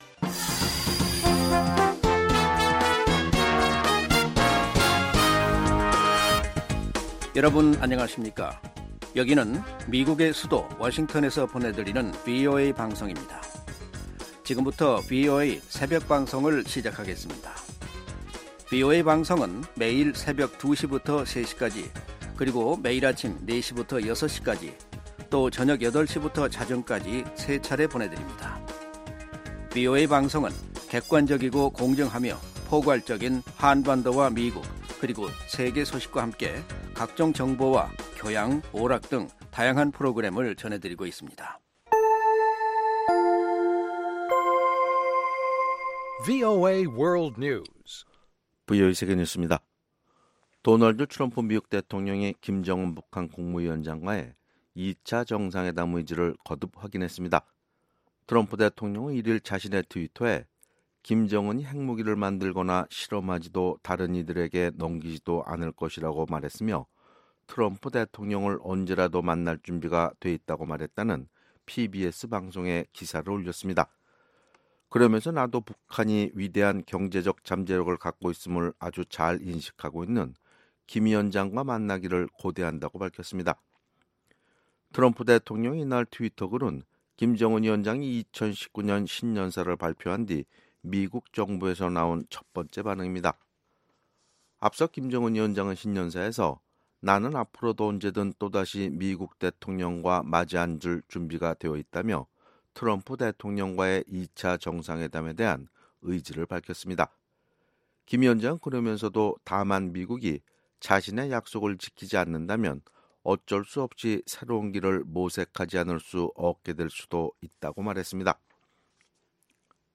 VOA 한국어 '출발 뉴스 쇼', 2019년 1월 3일 방송입니다. 김정은 북한 국무위원장은 신년사에서 트럼프 대통령과의 2차 정상회담에 대한 의지를 확인했으나 미국이 제재 압박을 계속하면 새로운 길을 모색할 수도 있다고 말했습니다. 민주당이 하원을 장악한 새 미국 의회에서는 상하원 외교·군사위원회 핵심 인사들이 대거 교체될 예정으로 대북 정책에 어떤 영향을 미칠지 주목됩니다.